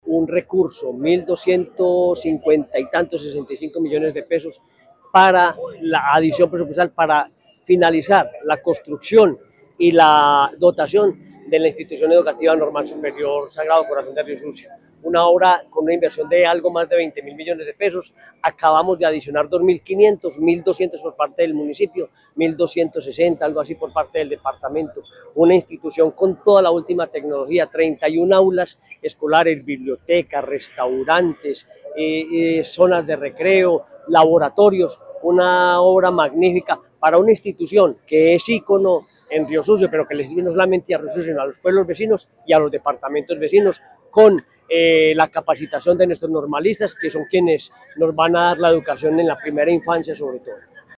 Henry Gutiérrez Ángel, gobernador de Caldas
Gobernador-de-Caldas-Henry-Gutierrez-Angel-recursos-IE-Normal-Sagrado-Corazon.mp3